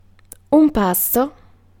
Ääntäminen
Synonyymit dîner Ääntäminen France (Paris): IPA: [ɛ̃ ʁə.pɑ] Tuntematon aksentti: IPA: /ʁə.pɑ/ IPA: /rə.pɑ/ Haettu sana löytyi näillä lähdekielillä: ranska Käännös Ääninäyte Substantiivit 1. pasto {m} Suku: m .